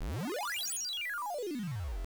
spring.wav